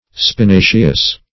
Search Result for " spinaceous" : The Collaborative International Dictionary of English v.0.48: Spinaceous \Spi*na"ceous\, a. (Bot.) Of, pertaining to, or resembling, the plant spinach, or the family of plants to which it belongs.